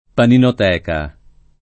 paninoteca [ paninot $ ka ] s. f.